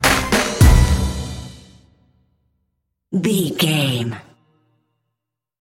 Aeolian/Minor
drum machine
synthesiser
hip hop
Funk
neo soul
acid jazz
energetic
bouncy
funky
hard hitting